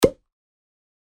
ButtonStandart.mp3